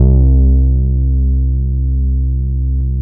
23SYN.BASS.wav